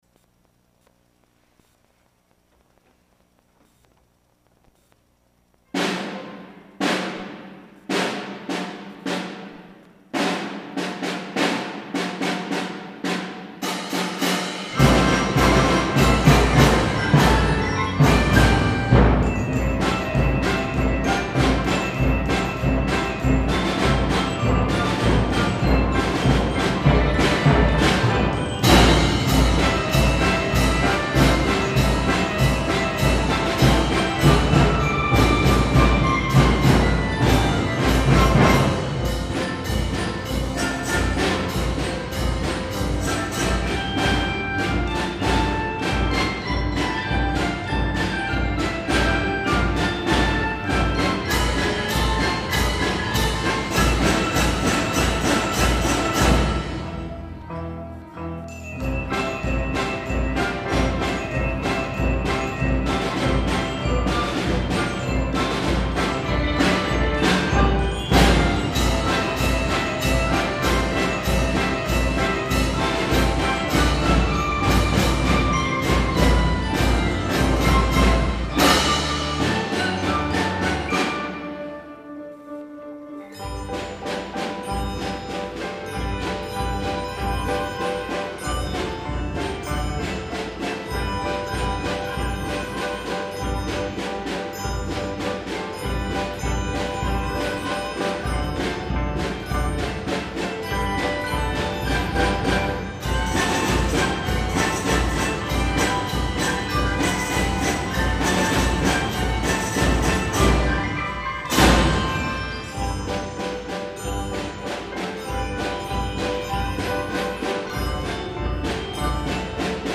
そして、5・6年生による合奏「
生放送で演奏を披露しました。
今年度初めて登場した、デスクオルガンやお琴チームもかっこいい音色を奏でました♪
とても迫力のある演奏に、アンコールの声が！！